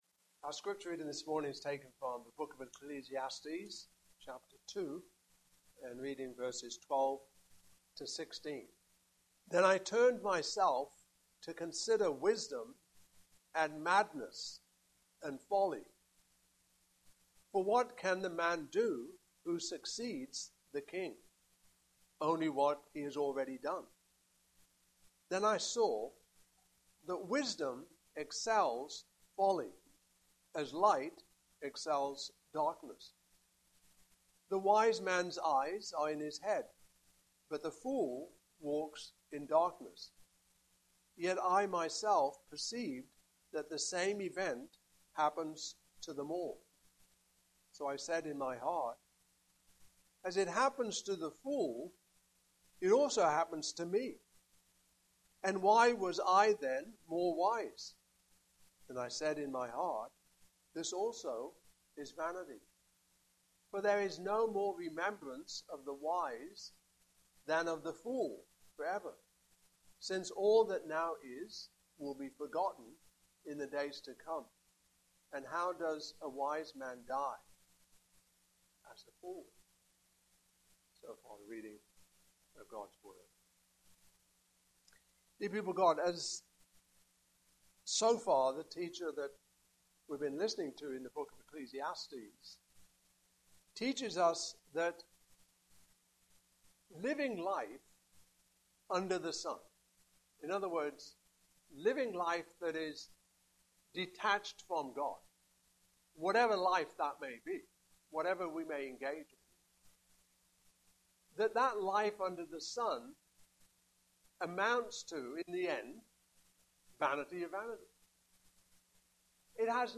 Passage: Ecclesiastes 2:12-16 Service Type: Morning Service